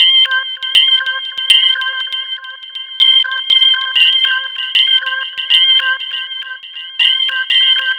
Astro 3 Organ-C.wav